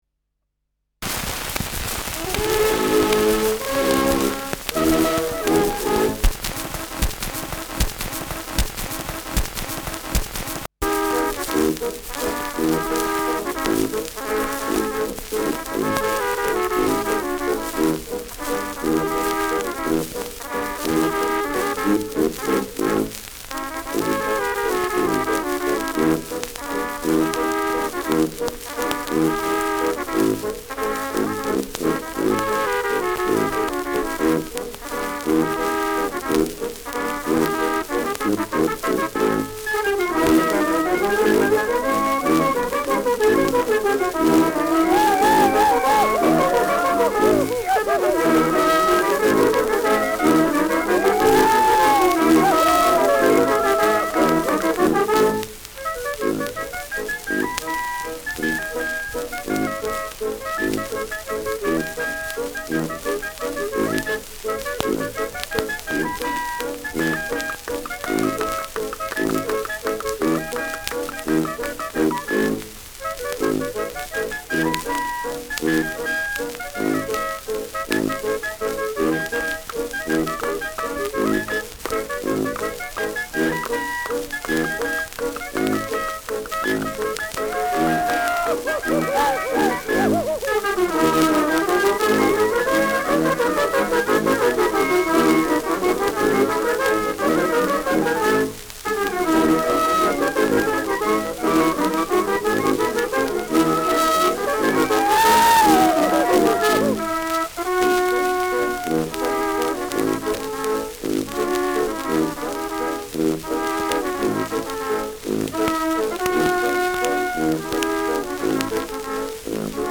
Schellackplatte
Tonrille: graue Rillen : Kratzer durchgängig
präsentes Rauschen : „Hängen“ 0’07’’-0’10’’
Kapelle Peuppus, München (Interpretation)